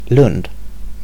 Ääntäminen
Ääntäminen Tuntematon aksentti: IPA: /lɵnd/ Haettu sana löytyi näillä lähdekielillä: ruotsi Käännös Ääninäyte Substantiivit 1. grove US Artikkeli: en .